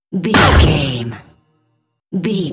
Cinematic stab hit trailer
Sound Effects
Atonal
heavy
intense
dark
aggressive
hits